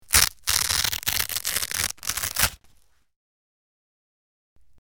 Velcro Rips Close Perspective, X5